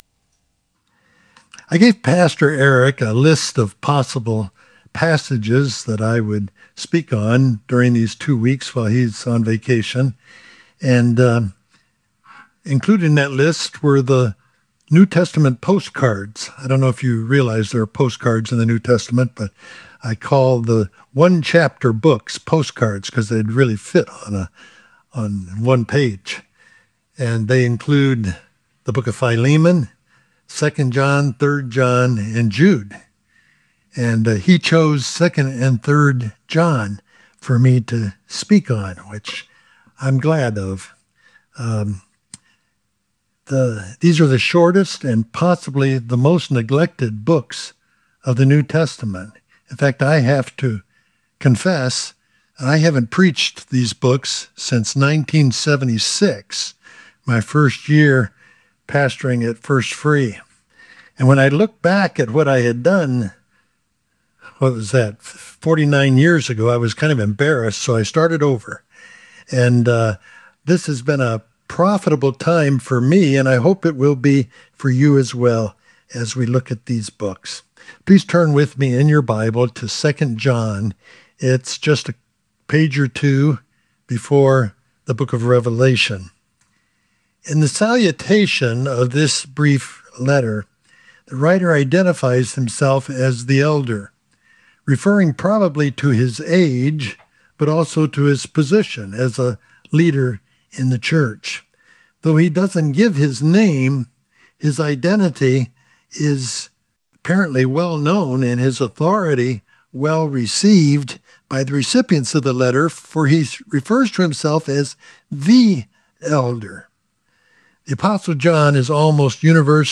PLACE: Beacon Church in Goddard, KS.